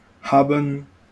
wymowa:
IPA/ˈhaːbən/ ?/i Imperfekt /ˈhatə/ Partizip IPA/gəˈhaːbt/